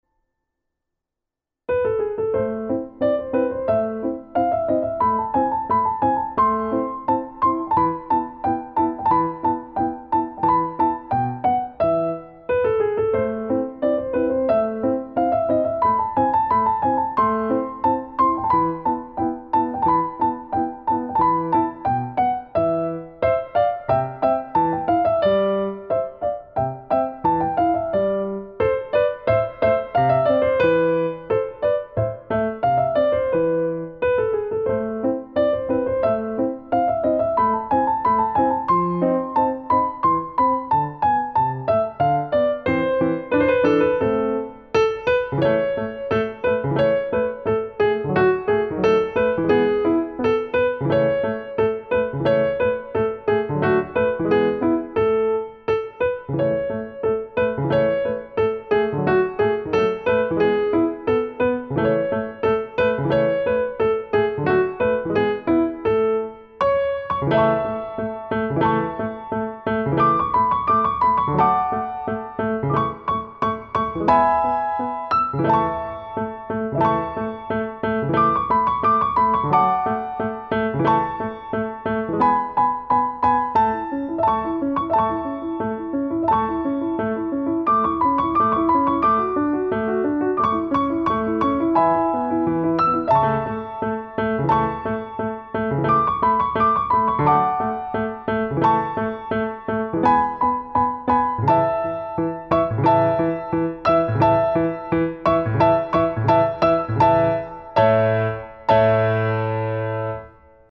Turecký pochod na klavír - Mozart